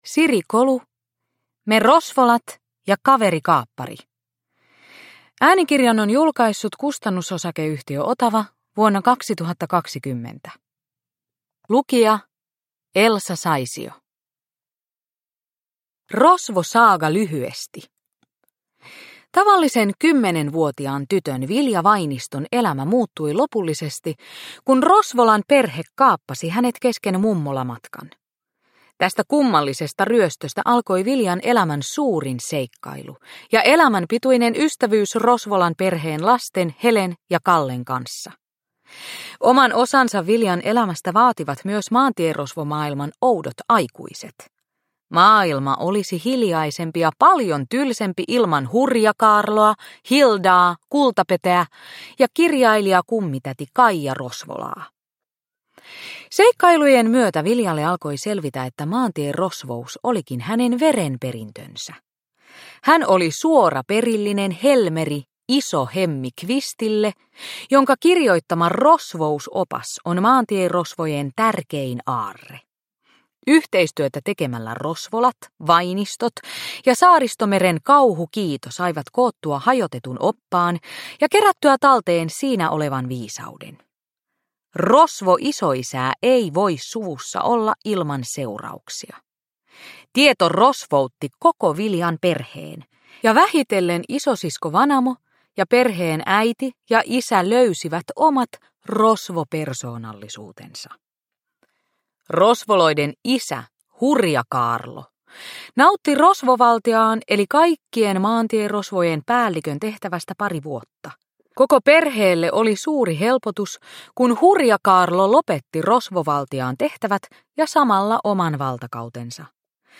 Me Rosvolat ja kaverikaappari – Ljudbok – Laddas ner